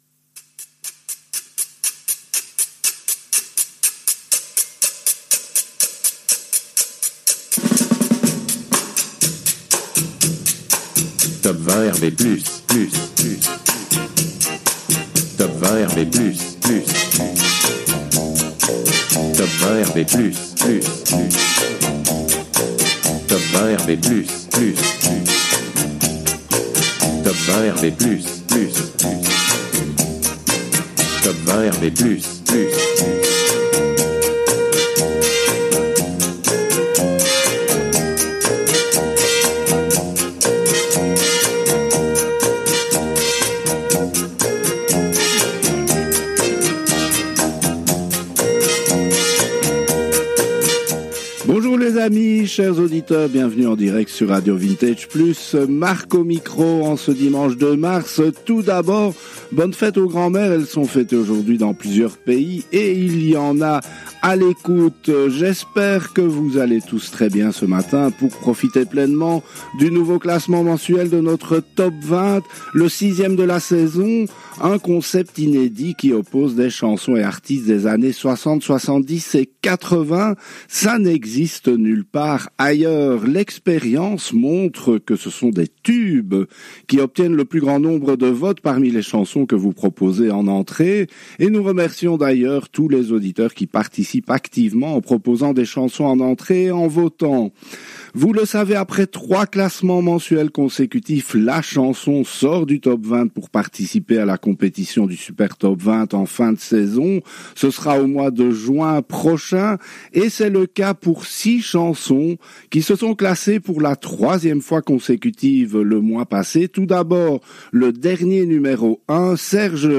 On établit également un classement des décennies pour voir si ce sont les 60’s, 70’s ou 80’s qui ont la préférence de nos auditeurs.